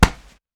Gloves Block Intense.wav